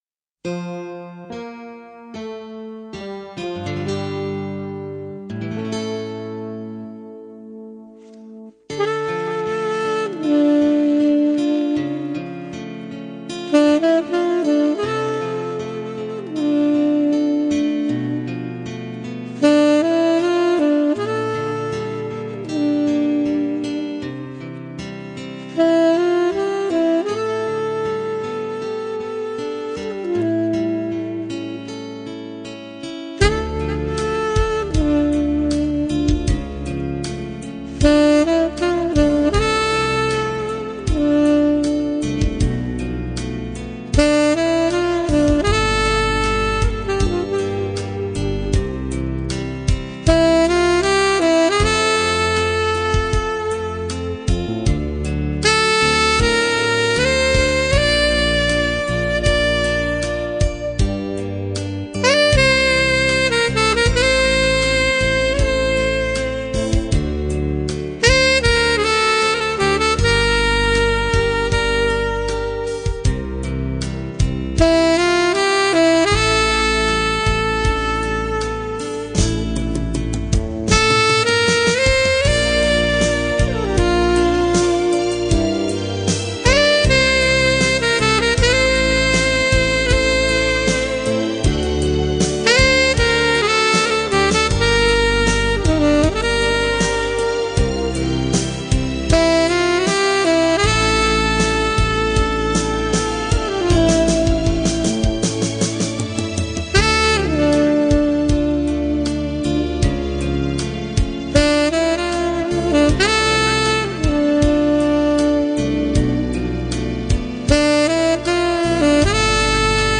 это инструментальная композиция в жанре эмбиент